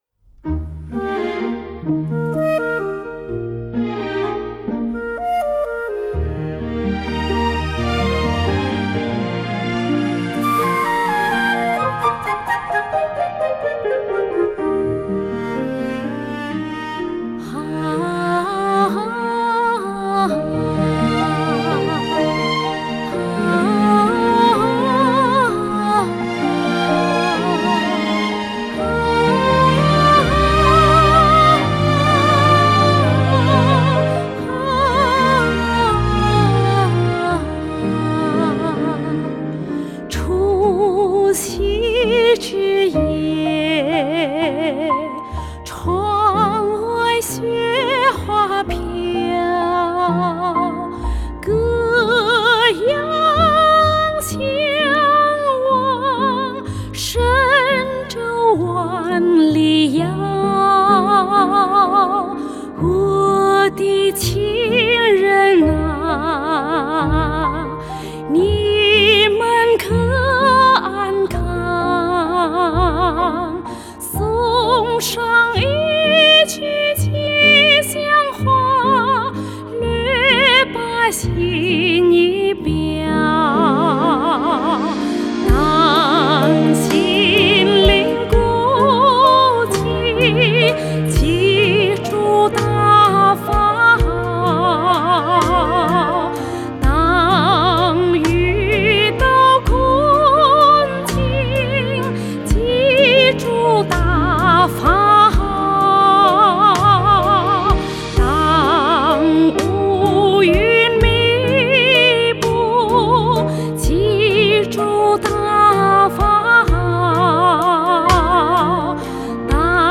女聲獨唱：吉祥話 | 法輪大法正見網